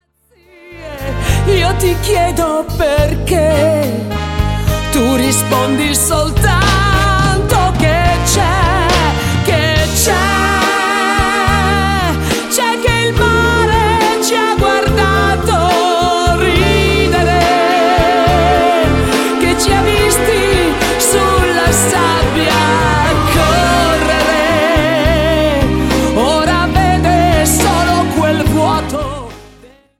LENTO  (4.02)